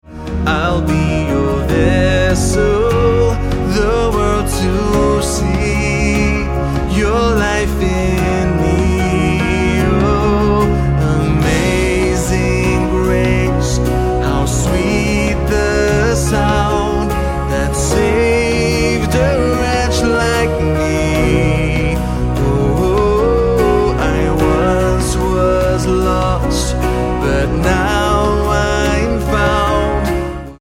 F#